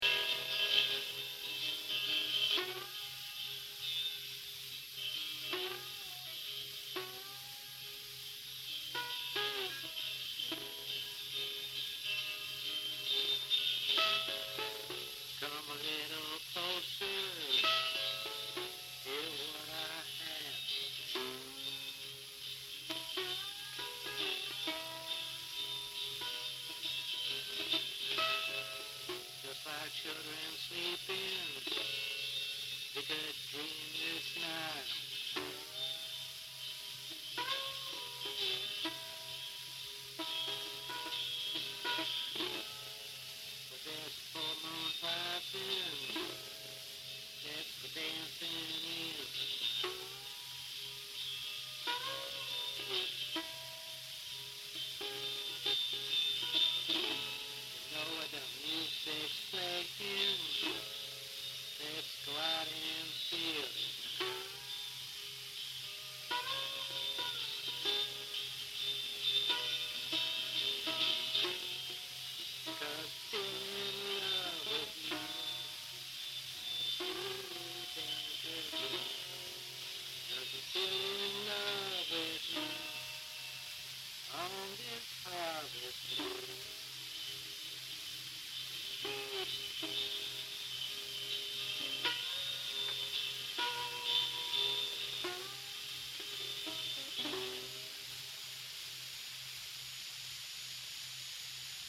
for Guitars, Mandolin, Accordion, & Harmonica
Percussion
- From The Original 78 rpm Acetate Records !